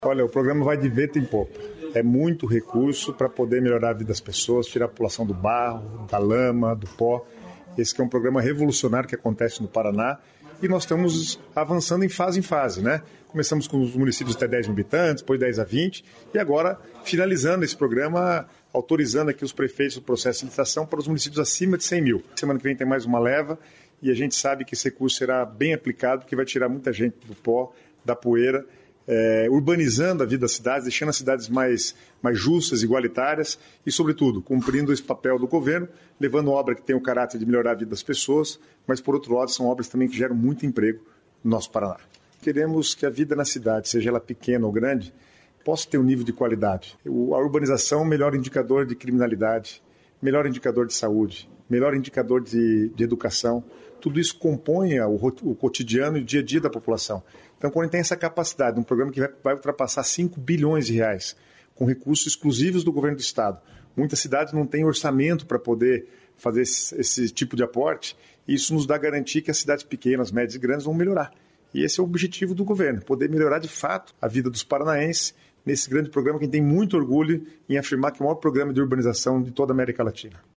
Sonora do secretário estadual das Cidades, Guto Silva, sobre o programa Asfalto Novo, Vida Nova